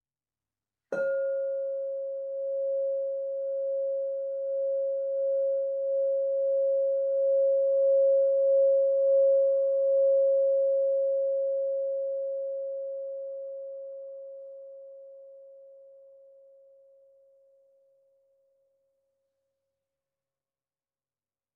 Meinl Sonic Energy 8" white-frosted Crystal Singing Bowl Om 136.10 Hz (PCSB8OM)
Om frequency: 136.10 Hz is tuned to the year tone of the earth year and corresponds to the cosmic primal sound Om.
Product information "Meinl Sonic Energy 8" white-frosted Crystal Singing Bowl Om 136.10 Hz (PCSB8OM)" The white-frosted Meinl Sonic Energy Crystal Singing Bowls made of high-purity quartz create a charming aura with their sound and design.